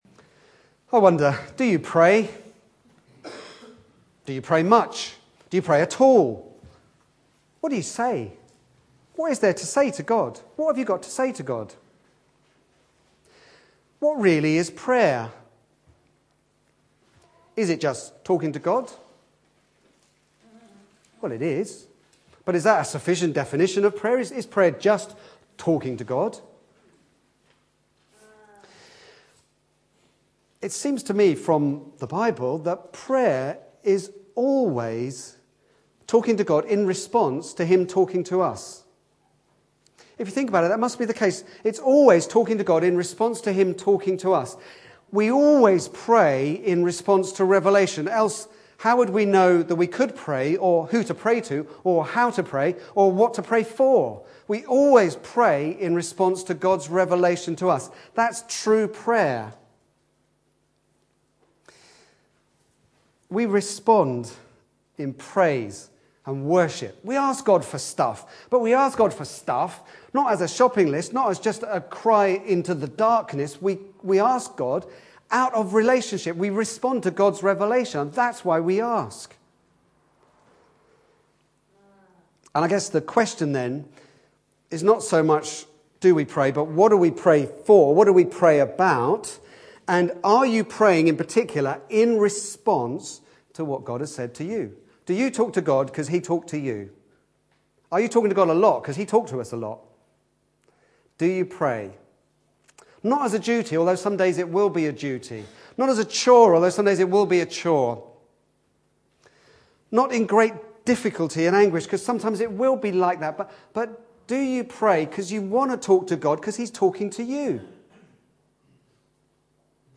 Back to Sermons A prayerful trust